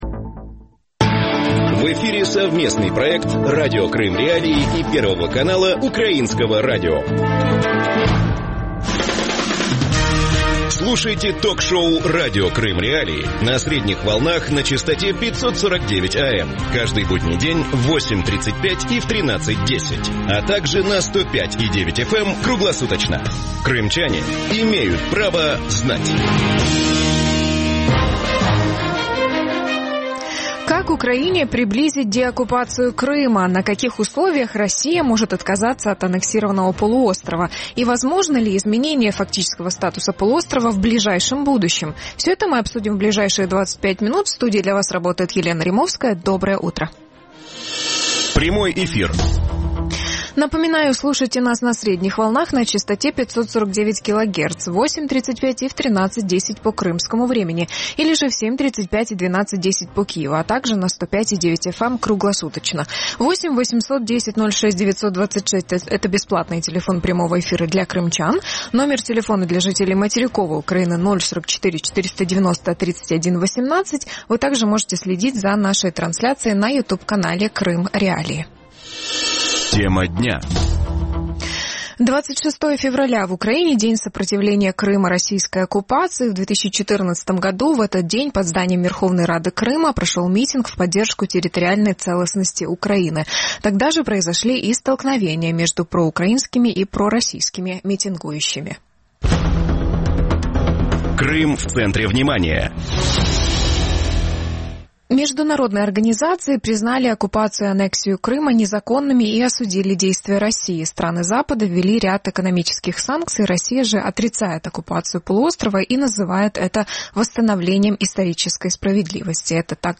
Гости эфира: Изет Гданов, заместитель постоянного представителя президента Украины в Автономной Республике Крым; Андрей Сенченко, председатель общественной организации «Сила права», экс-вице-премьер автономной Республики Крым; Константин Боровой, российский оппозиционный политик, лидер партии «Западный выбор»; Сергей Высоцкий, народный депутат Украины.